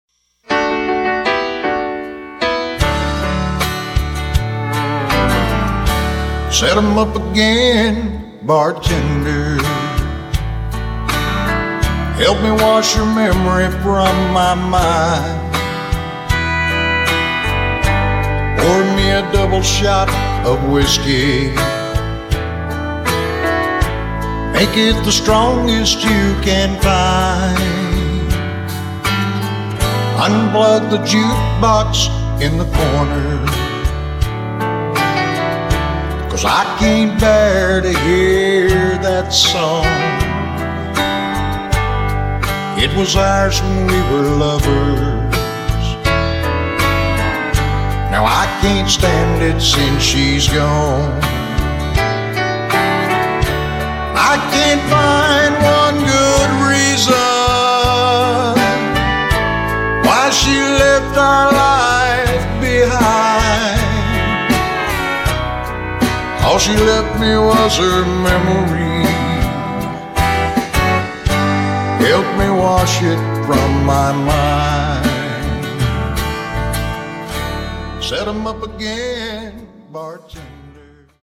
A ballad style, lost love, drinking song.